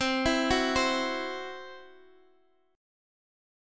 CMb5 chord